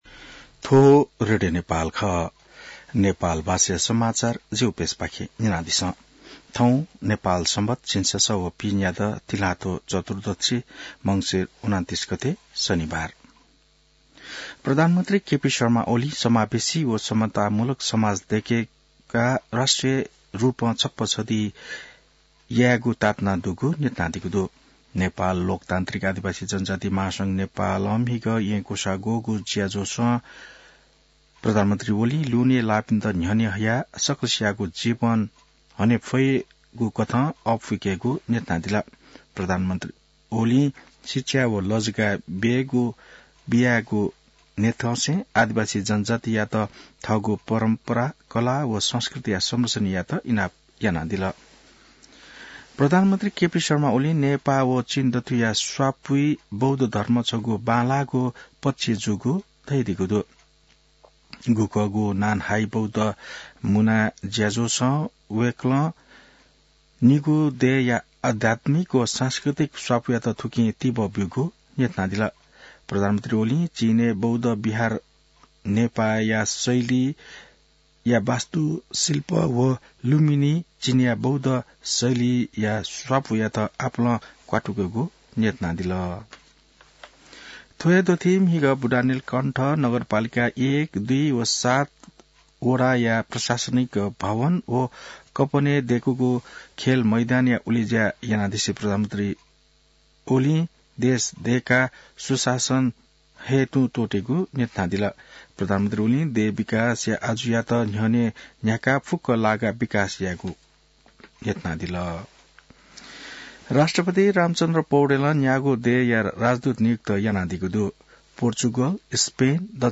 नेपाल भाषामा समाचार : ३० मंसिर , २०८१